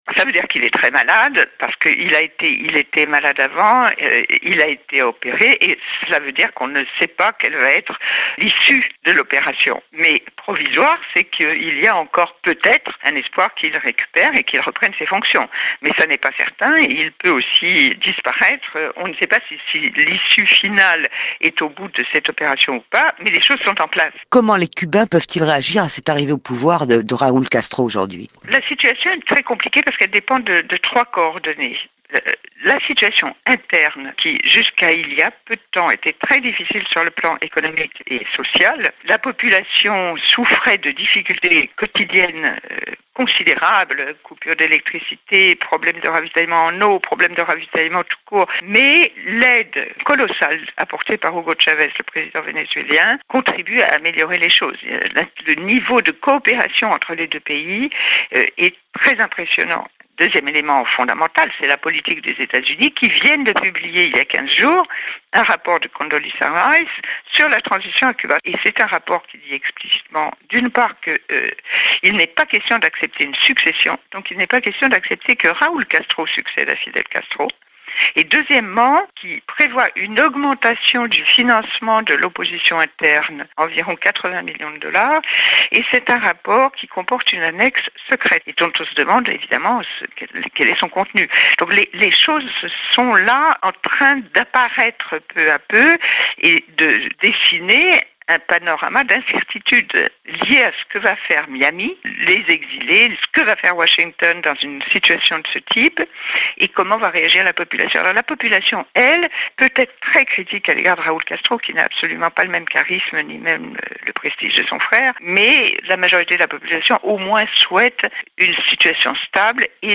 Entretien paru sur le site Radio France, le 1er août 2006.